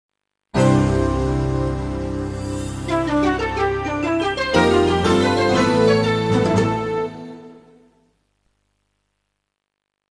philips-cdi-startup-sound.wav